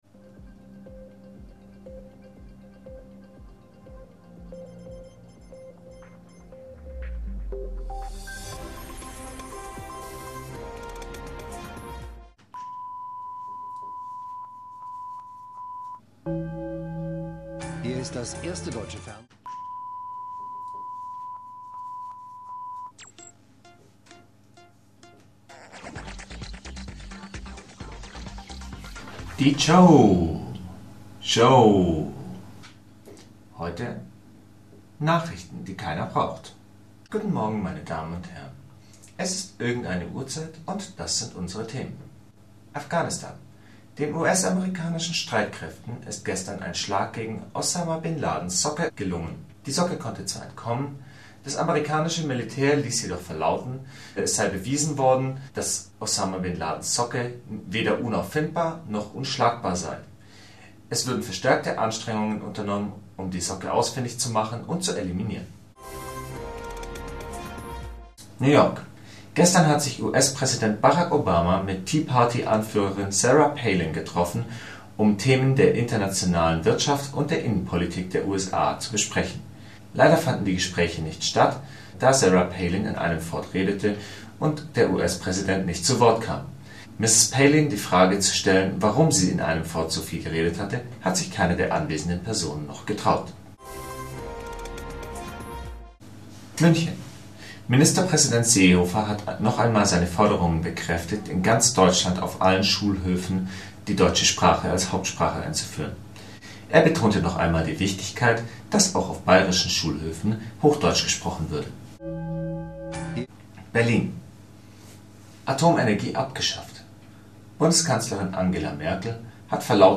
In diesem kurzen Nachrichtenstück erscheinen so welterschütternde Meldungen wie die Abschaffung von Stuttgart 21 oder des Atomstroms in Deutschland. Wenn es nur keine Satire wäre...